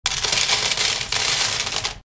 counting-of-money-short.mp3